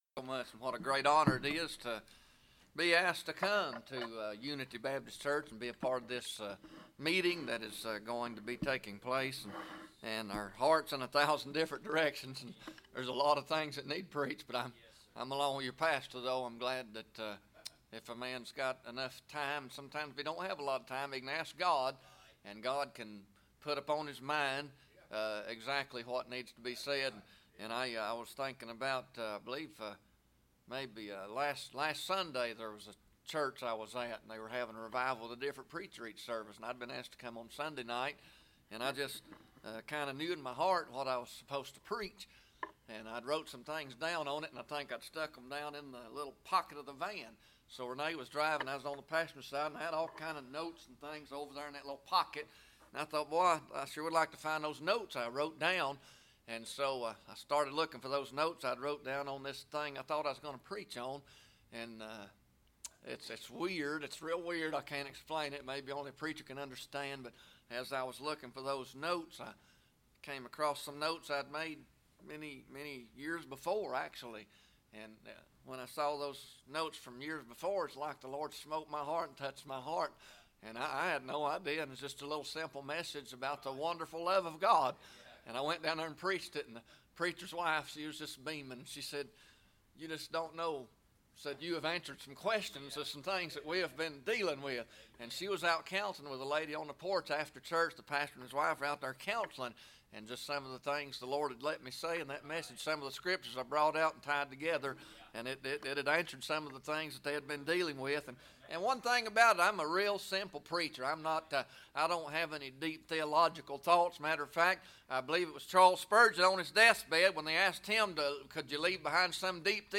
Psalm 51 Service Type: Sunday Morning Bible Text